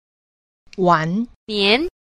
6. 晚年 – wǎnnián – vãn niên (tuổi già)